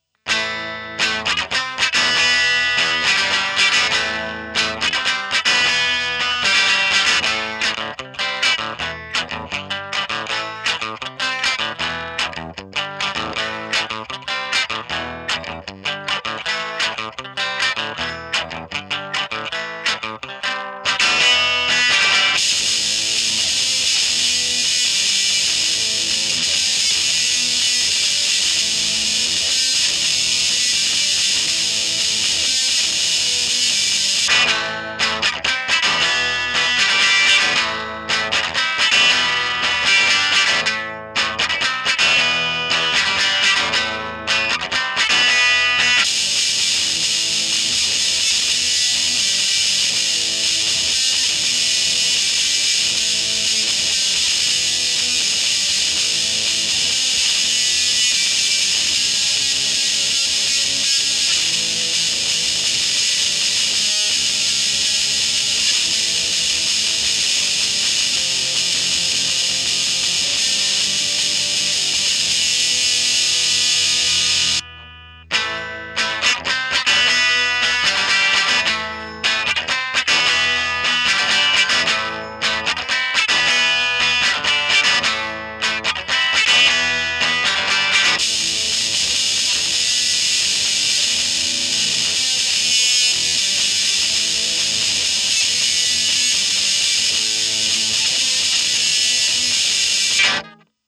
I also used my stock 1980 Twin Reverb for just one recording and uploaded the results.
-SM57
Everything was recorded with the scarlet solo.
TwinReverb Dust Cap Edge.mp3